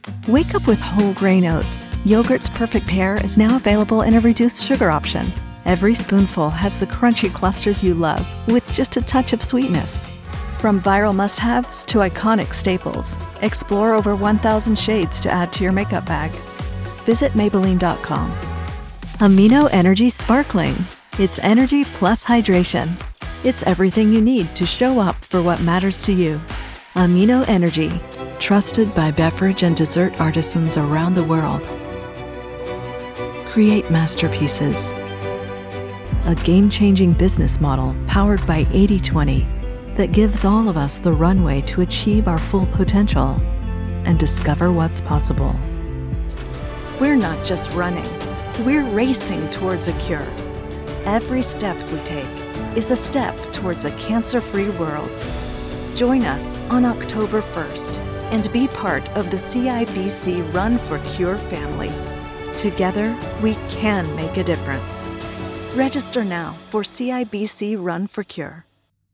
English (American)
Commercial, Natural, Reliable, Friendly, Warm
Commercial